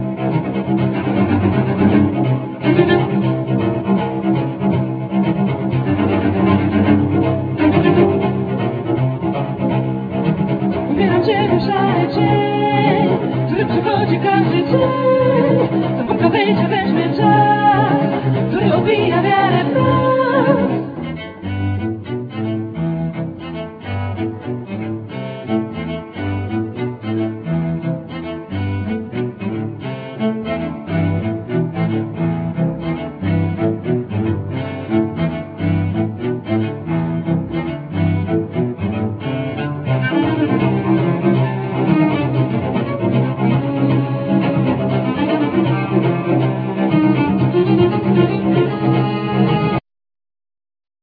Cello,Vocals